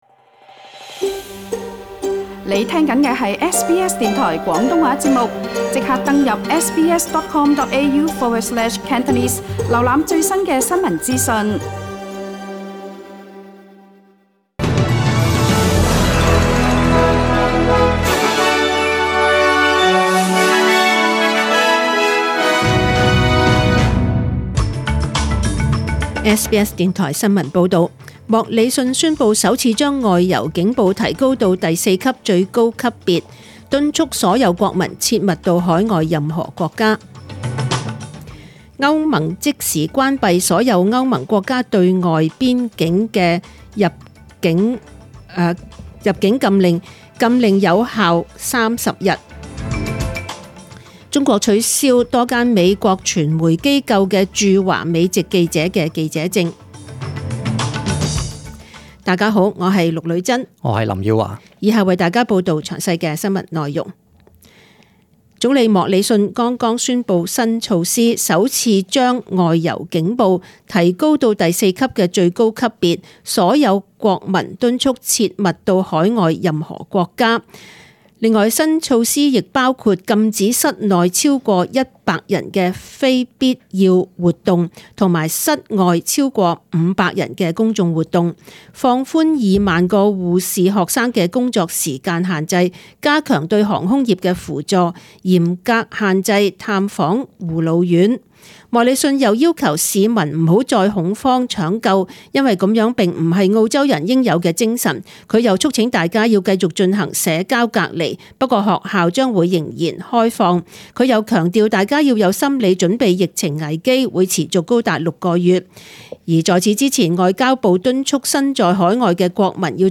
请收听本台为大家准备的详尽早晨新闻
SBS 廣東話節目中文新聞 Source: SBS Cantonese